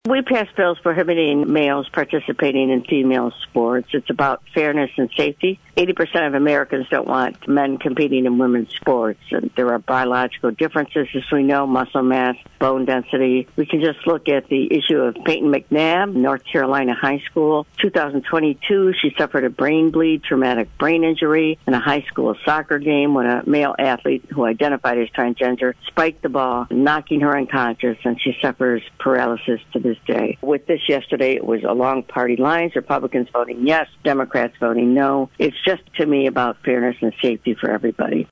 This full interview is available to listen to and download on this website, and State Representative Kathy Schmaltz regularly joins A.M. Jackson on Friday mornings.